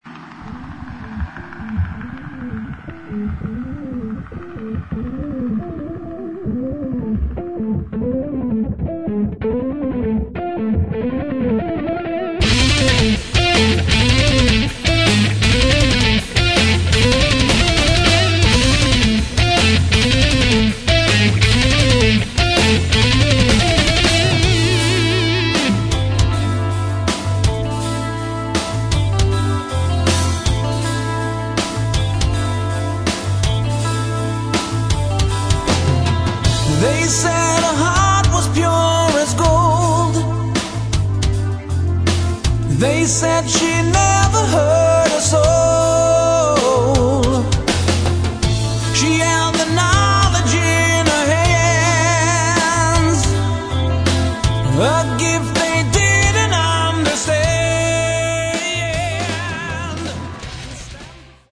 гитара, клавиши, бас
вокал
барабаны